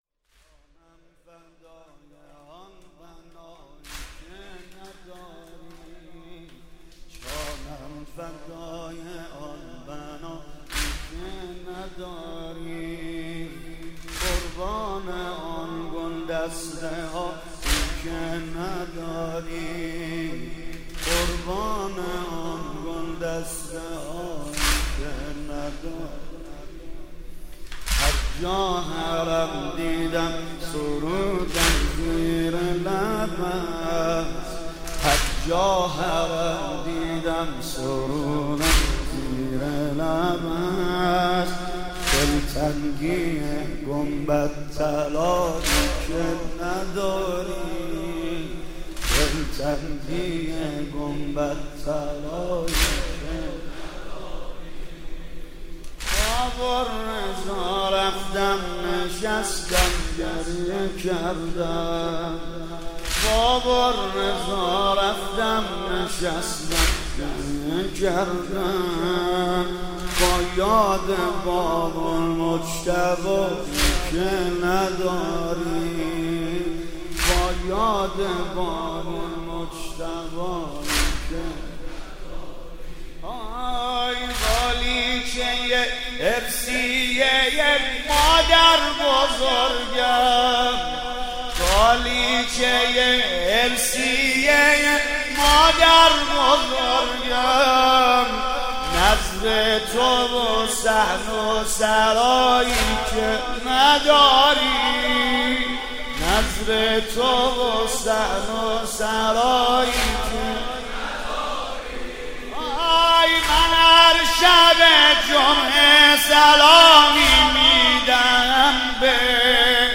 «ویژه مناسبت تخریب بقیع» واحد: جانم فدای آن بنایی که نداری